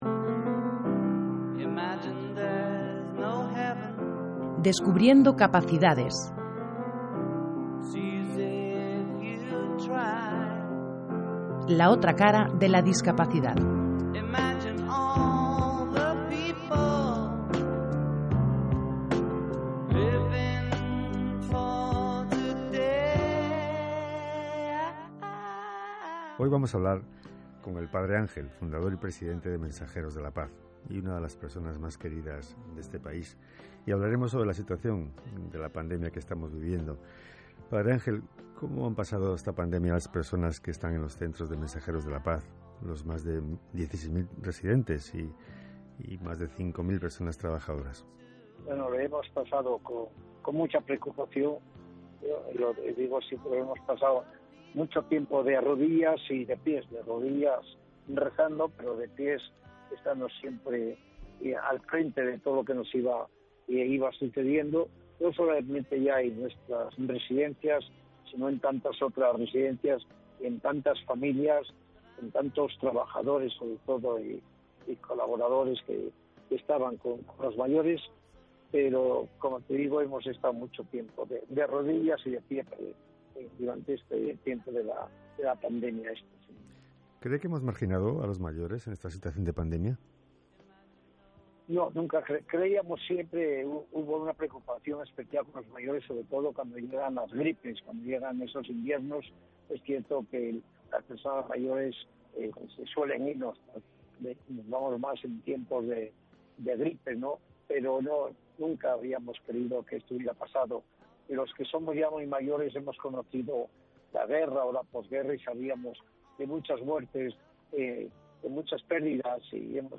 Entrevista al Padre Ángel